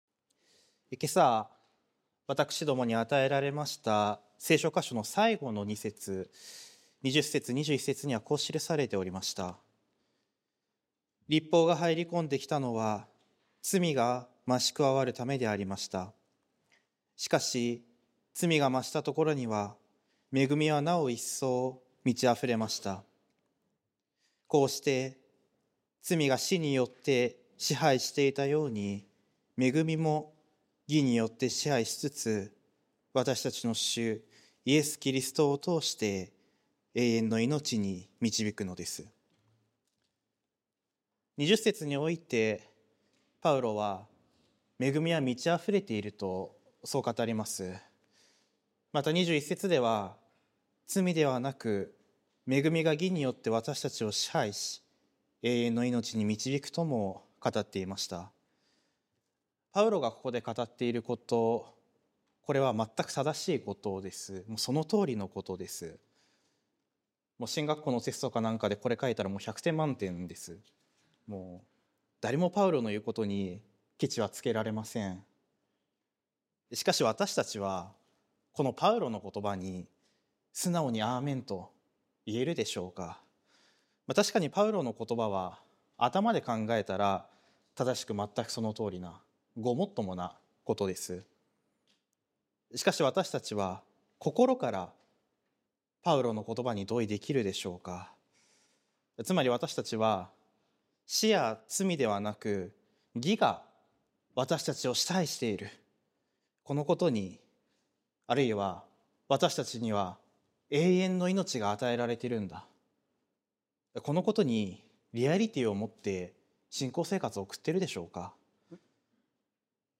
sermon-2025-03-30